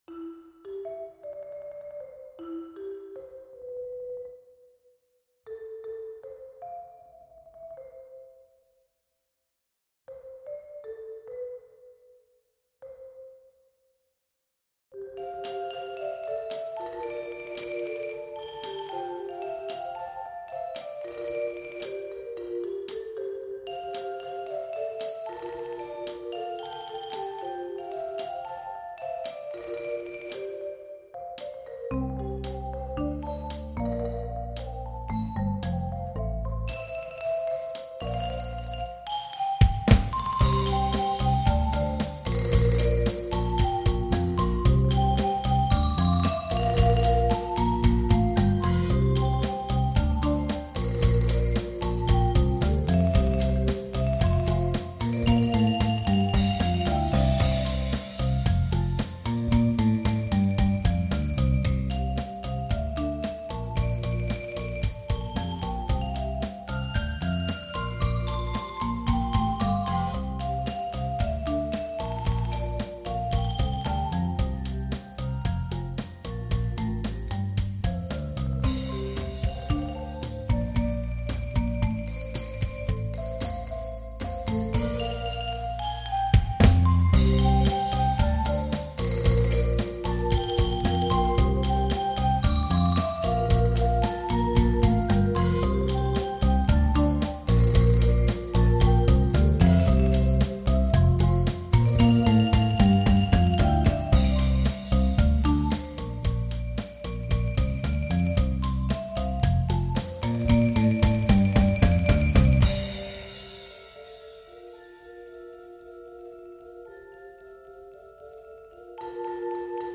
Mallet-Steelband Muziek